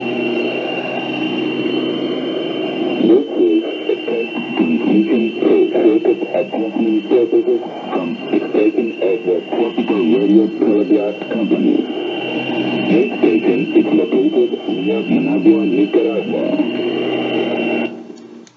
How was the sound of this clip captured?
In 1972, while doing a random band scan on shortwave, I came across a point-to-point radio station from Managua, Nicaragua that was transmitting a repeating test message (recording attached below):